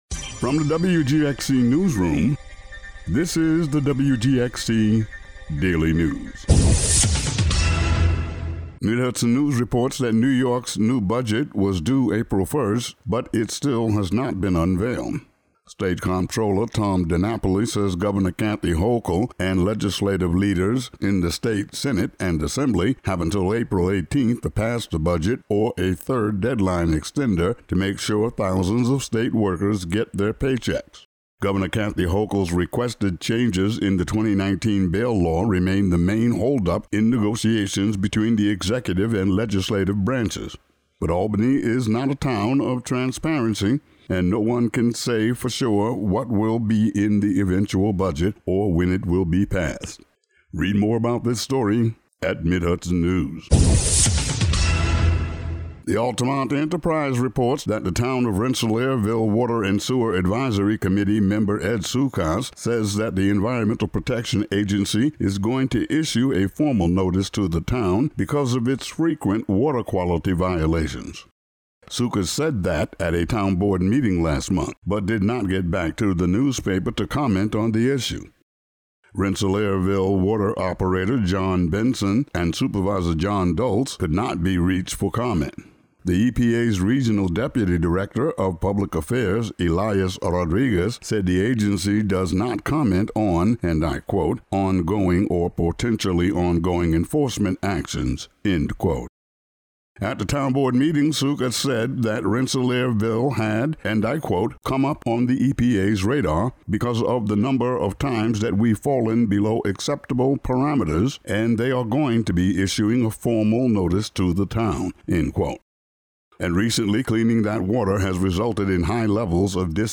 Today's daily news audio update.
Today's daily local audio news.